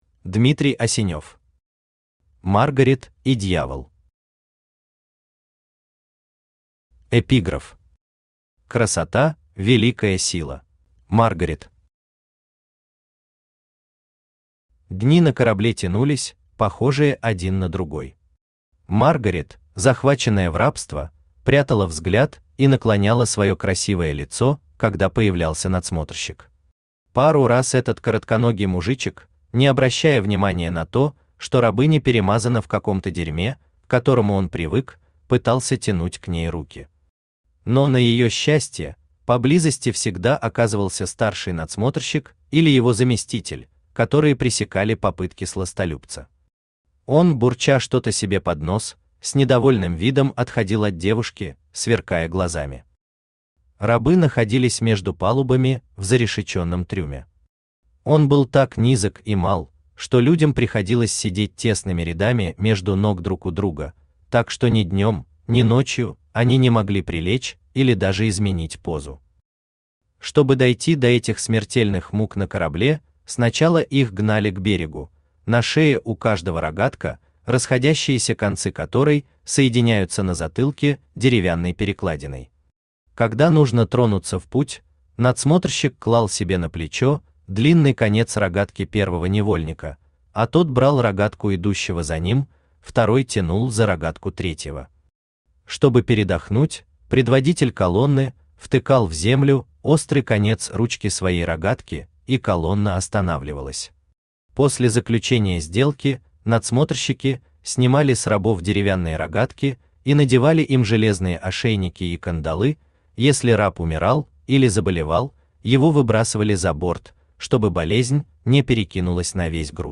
Aудиокнига Любовница Автор Дмитрий Асенев Читает аудиокнигу Авточтец ЛитРес.